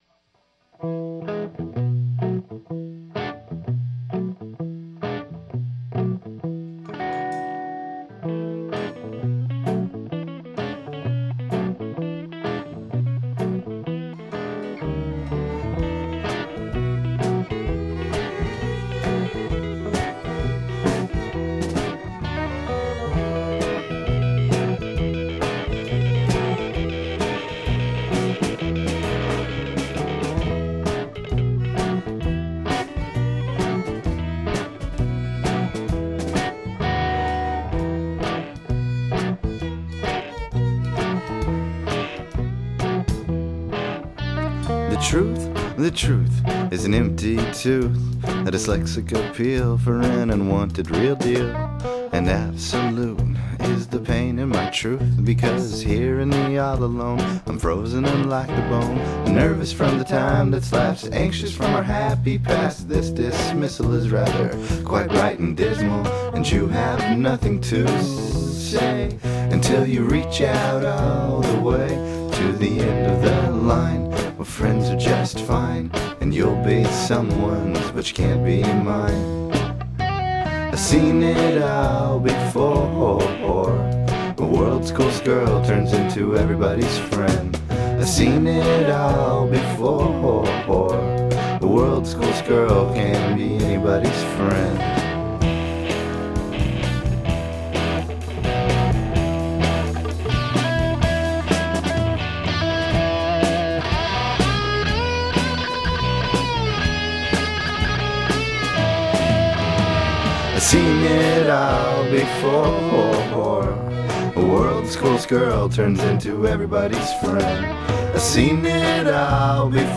Americana